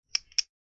vol. 1 " Pen click
描述：用笔点击。使用CA桌面麦克风录制。